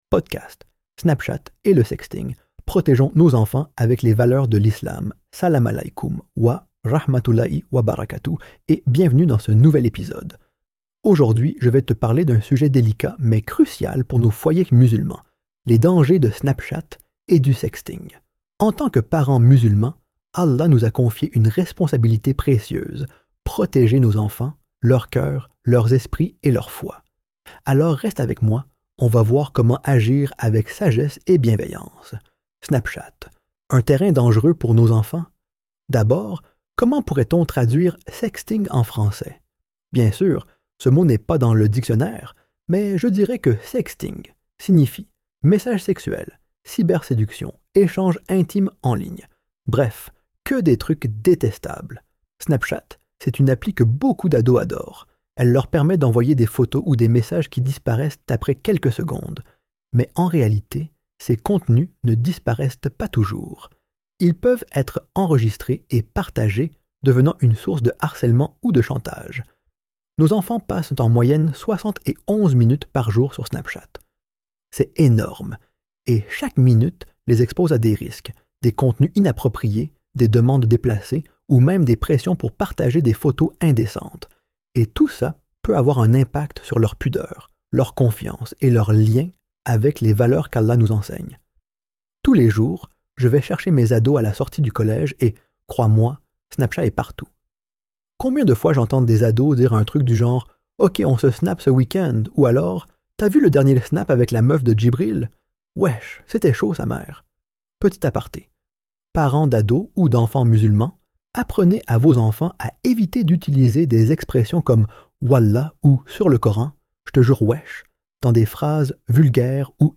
Podcast musulman